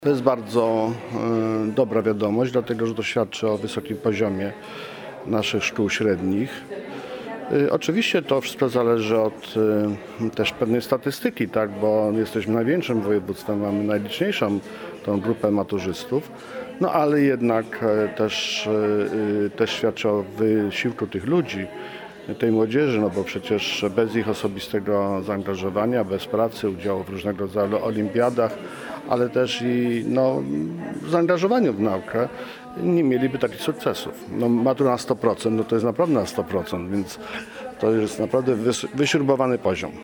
Wypowiedź Adama Struzika: